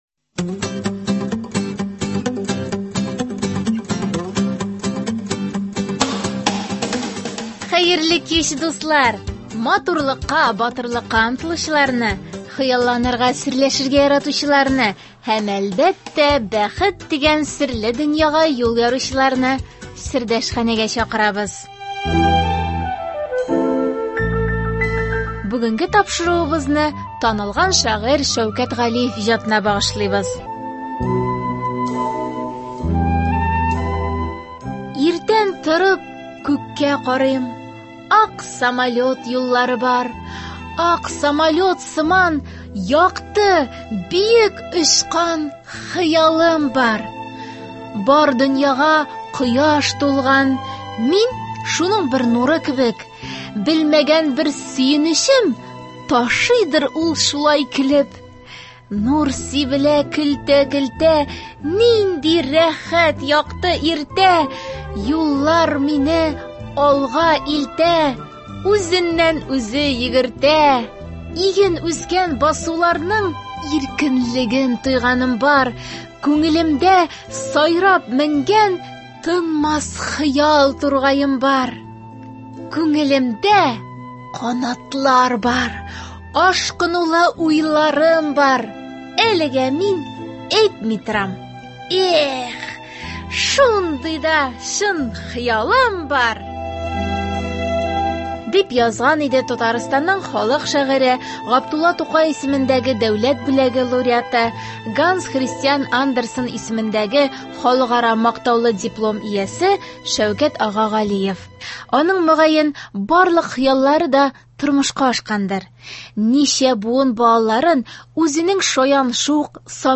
Тапшыруда авторның үз язмасындагы шигырен дә ишетерсез.